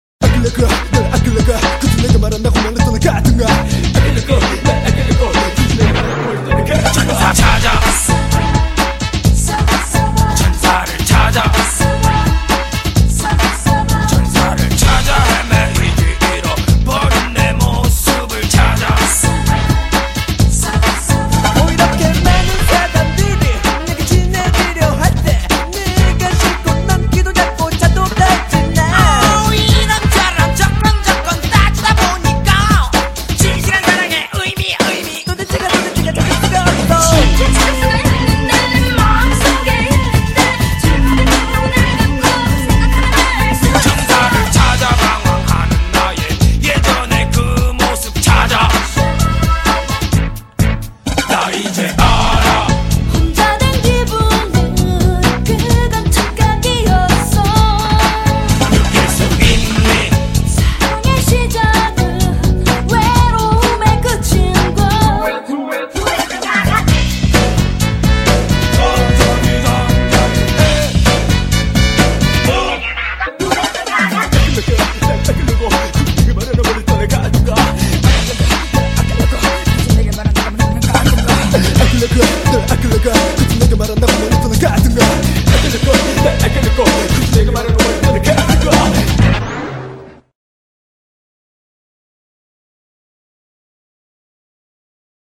BPM129--1
Audio QualityPerfect (High Quality)